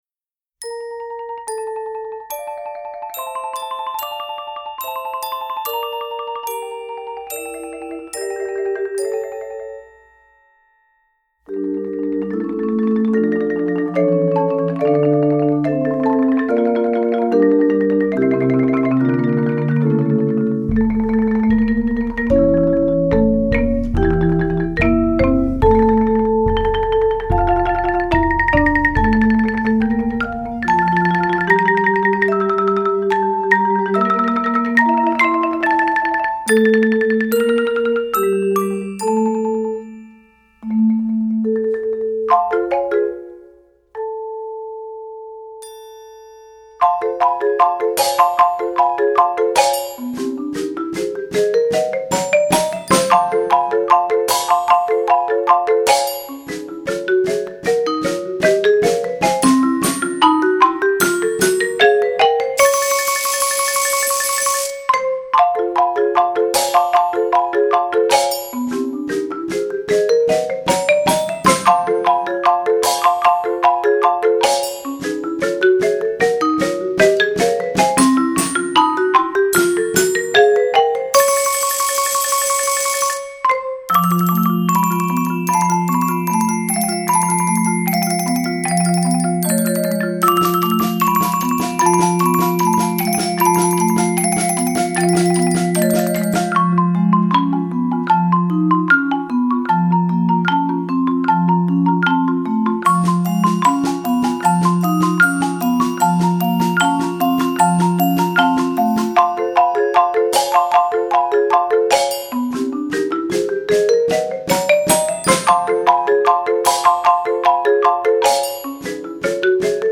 Voicing: 6-7 Percussion